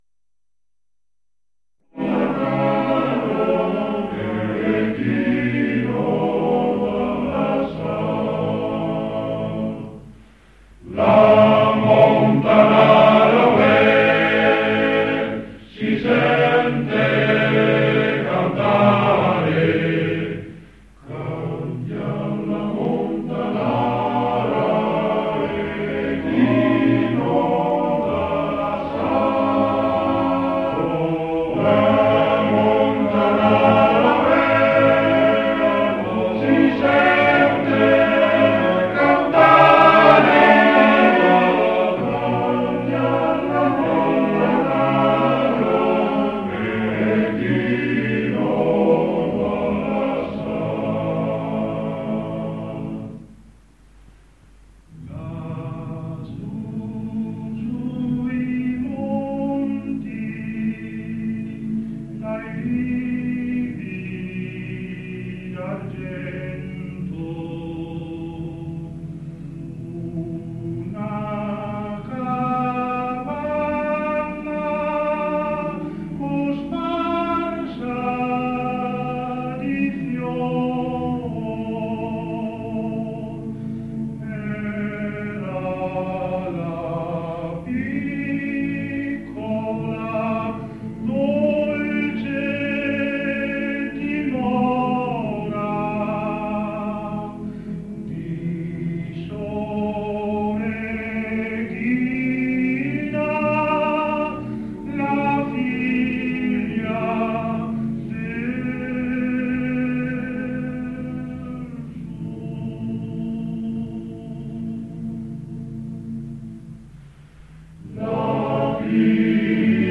Post 1953. 1 bobina di nastro magnetico.